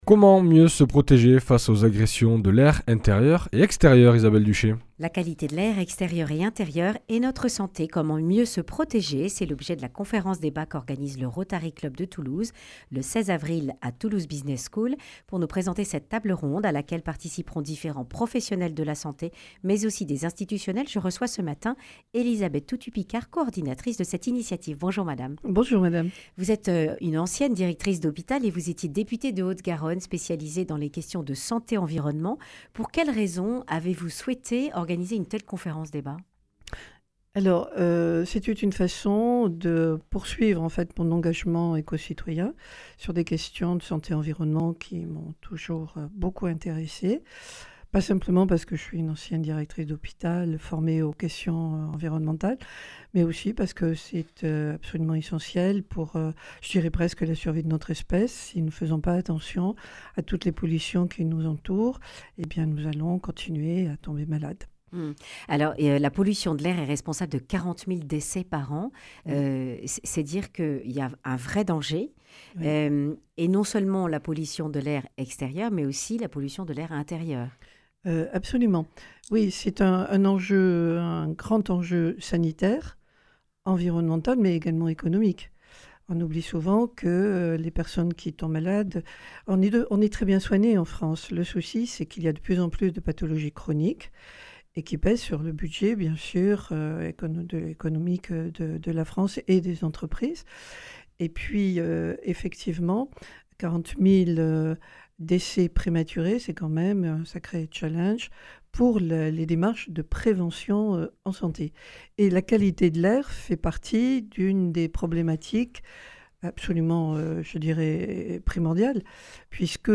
Accueil \ Emissions \ Information \ Régionale \ Le grand entretien \ Qualité de l’air : comment mieux s’en protéger ?